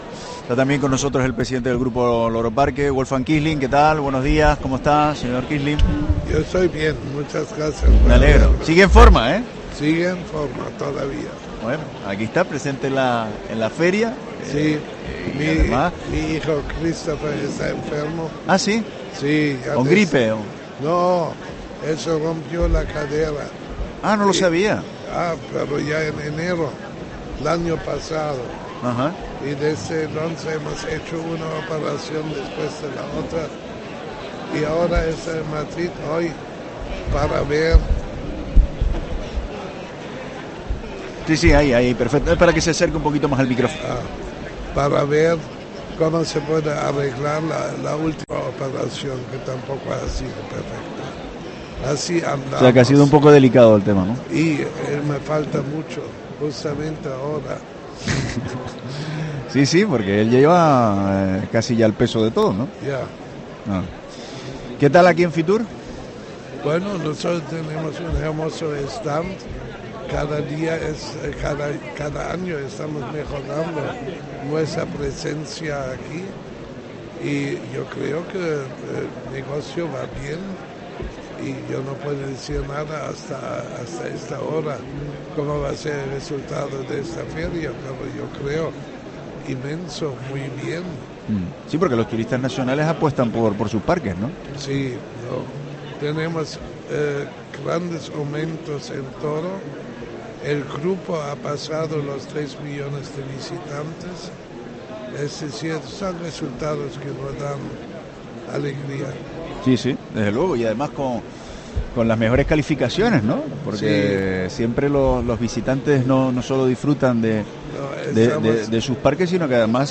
Entrevista
en FITUR 24